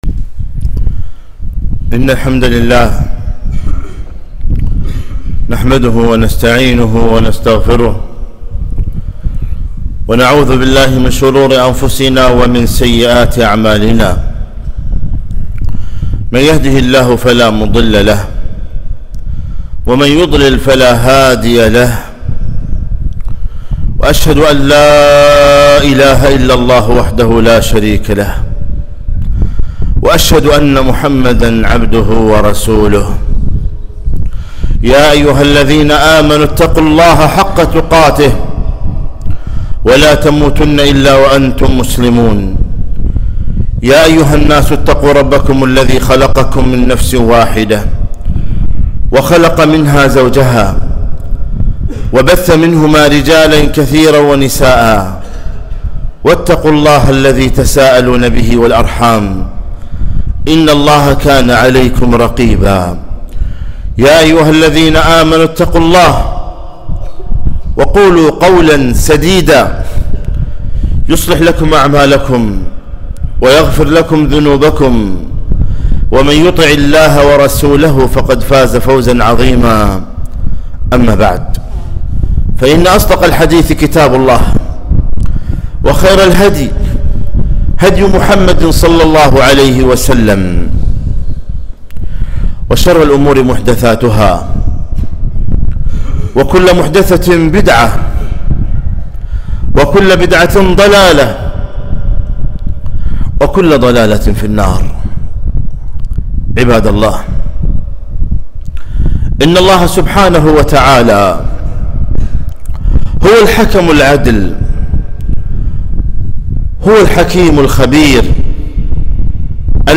خطبة - الدعاء السلاح الذي لا يُقهر